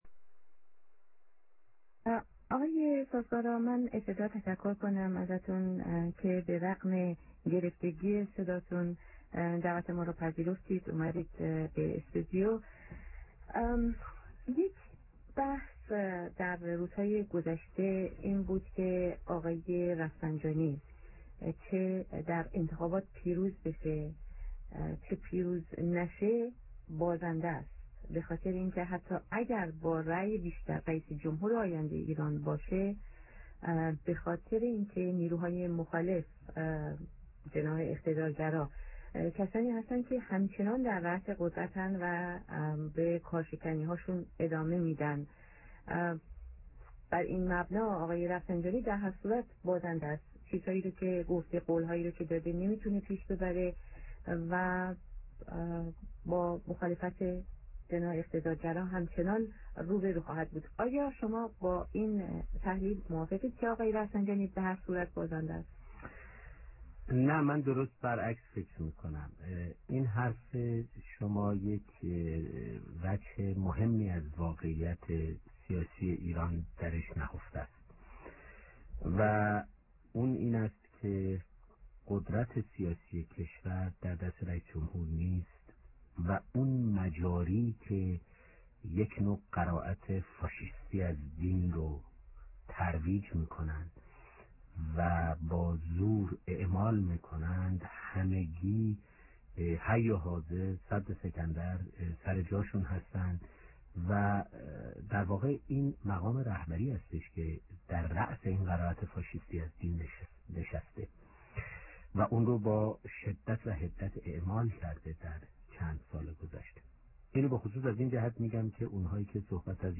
(برای شنیدن این مصاحبه نیمساعته از لینک‌های صدا استفاده کنید)